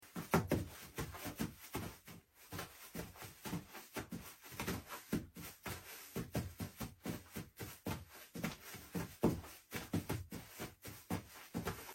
144 Foley for a tv sound effects free download